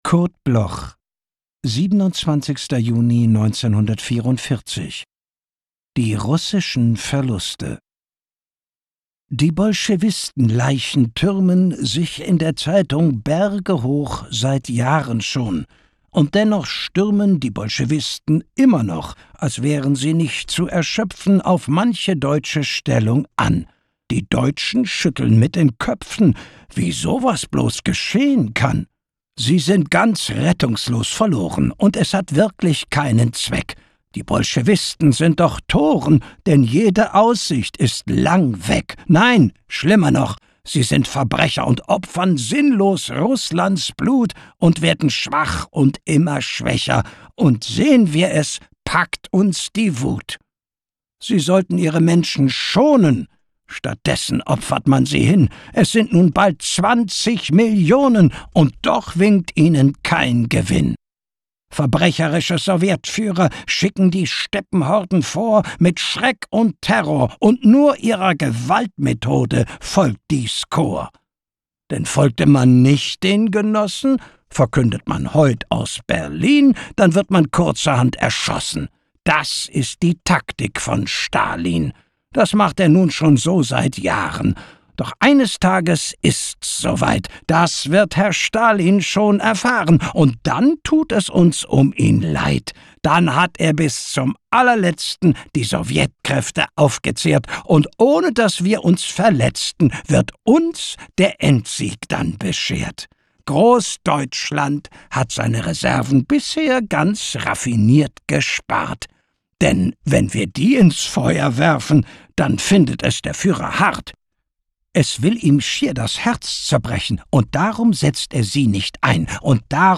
Recording and Editing: Kristen & Schmidt, Wiesbaden